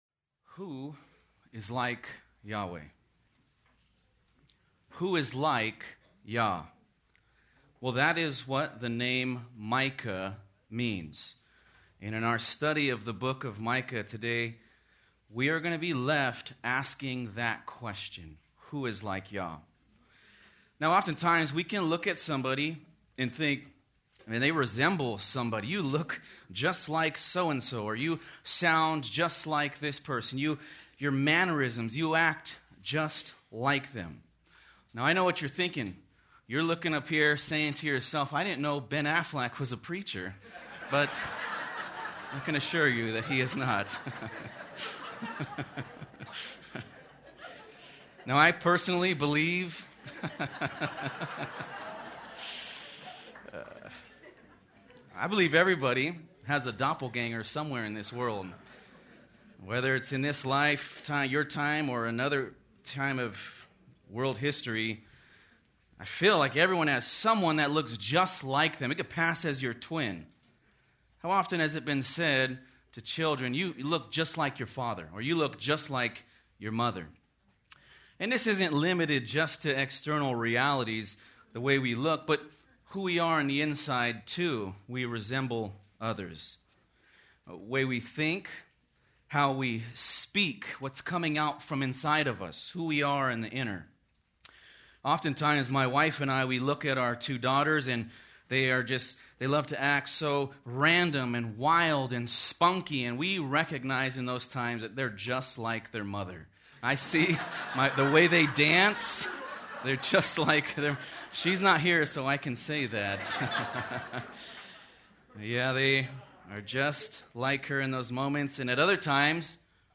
Recent sermons from Sojourners, a ministry of Grace Community Church in Sun Valley, California.